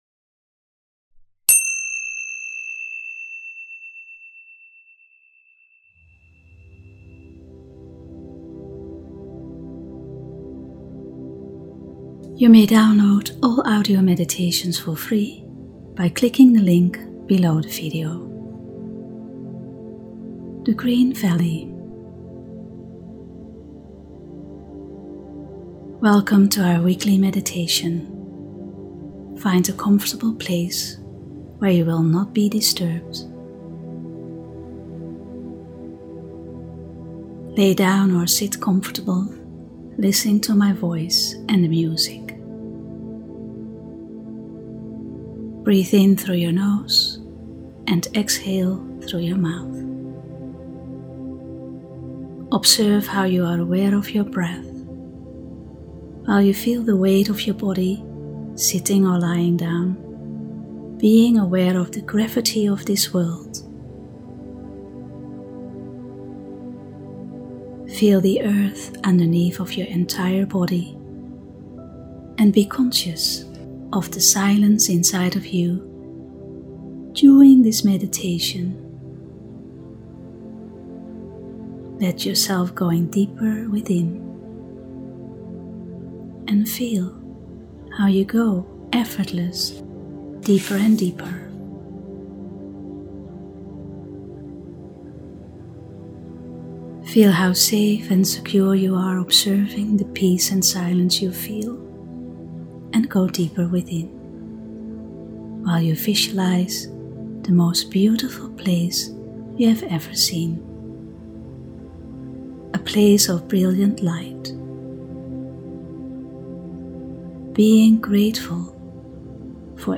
Meditation “The green valley”